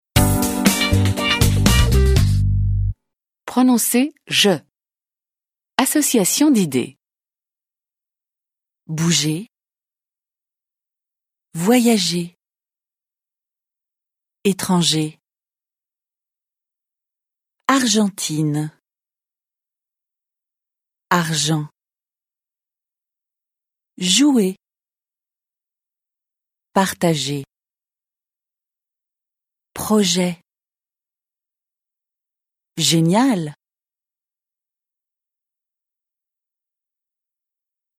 Pronnoncez [ʒ]
به تلفظ صدای ژ در کلمات زیر دقت کنید: